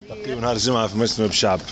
لمّح رئيس الحكومة المكلف يوسف الشاهد لمراسل "الجوهرة اف أم" منذ قليل إلى أنه لن يدخل أي تغييرات في تركيبة حكومته التي أعلنها نهاية الأسبوع الماضي.
وأضاف في تصريح مقتضب أن الحسم سيكون يوم الجمعة المقبل في إشارة للجلسة العامة التي ستعقد الجمعة المقبل لمنح حكومته ثقة السلطة التشريعية.